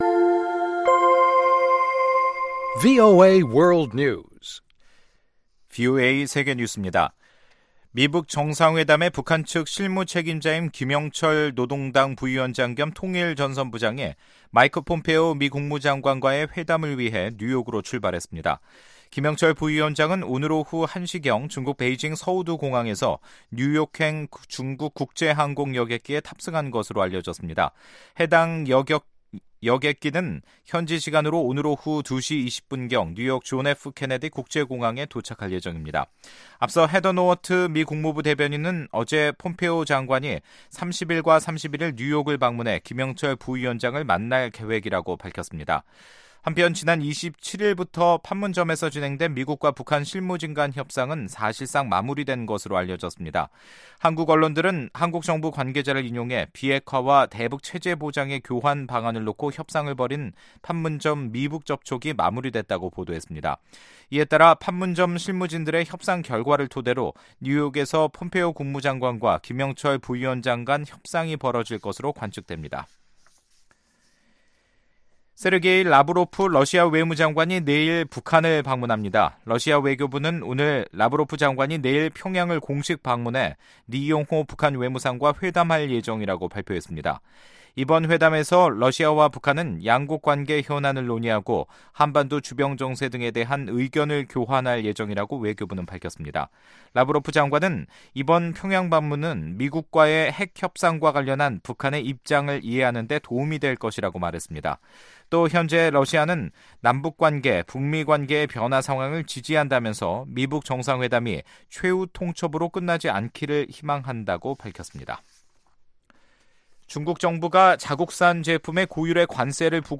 세계 뉴스와 함께 미국의 모든 것을 소개하는 '생방송 여기는 워싱턴입니다', 2018년 5월 30일 저녁 방송입니다. ‘지구촌 오늘’에서는 미국 정부의 중국산 기술제품 고율 관세 시행, ‘아메리카 나우’에서는 트럼프 변호인인 루돌프 줄리아니가 트럼프 대통령의 대면 조사에 새로운 조건을 내걸었다는 소식 전해드립니다. '타박타박 미국 여행'에서는 미국의 가장 작은 보석 같은 주, 로드아일랜드를 찾아갑니다.